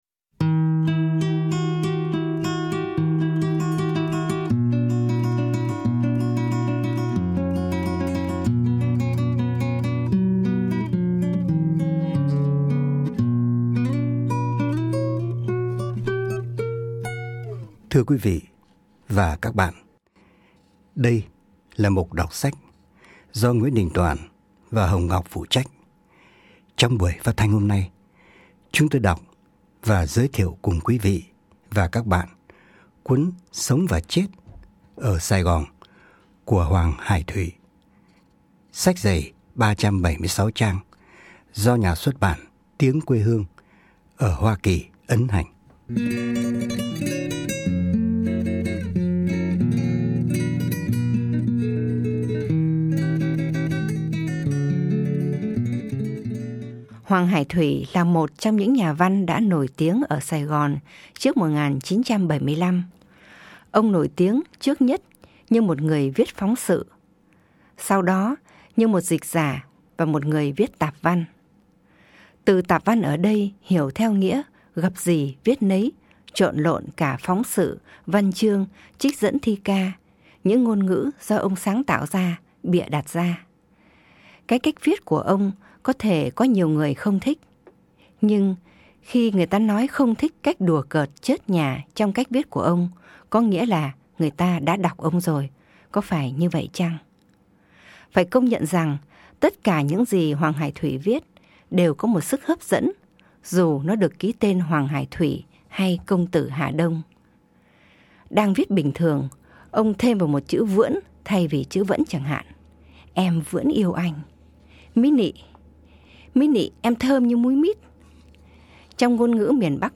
Ở cái giới hạn của thời lượng phát thanh, giọng của người đọc trở thành một yếu tố quan trọng trong việc chuyển tải nội dung. Giọng NĐT không còn là giọng một thanh niên ủ rũ héo sầu ẩm ướt sương buồn tới nỗi sém nhão nhẹt, và những lời ông viết không còn là những lời diễm ảo cho tới nỗi sém cải lương. Phải nghe mới biết, NĐT như một ca sĩ biết truyền cảm xúc cho người nghe khi hát, ở cái cách nhấn chữ nhả câu, ở những khoảng cắt ngừng hay lơi giọng, đây _ một người đàn ông trải đời với hồn cảm nhận thâm sâu, nói những lời đau mong với tới muôn ngàn những bóng người vô danh trong cuộc sống.